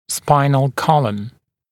[‘spaɪnl ‘kɔləm][‘спайнл ‘колэм]позвоночный столб, позвоночник